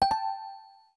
appkefu_dingdong.wav